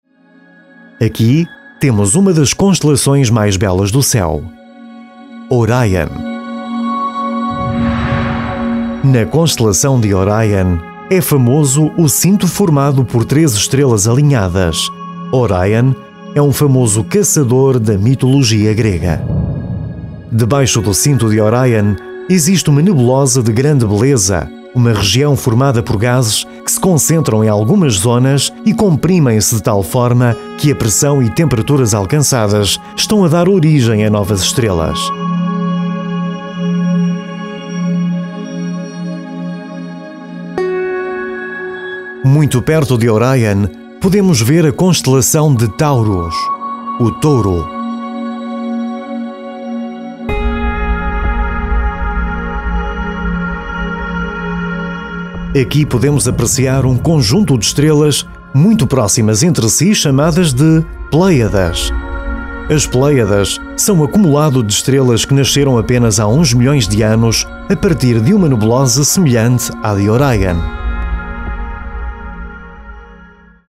Sprechprobe: eLearning (Muttersprache):
His voice has been described as warm, smooth, sophisticated, natural and youthful.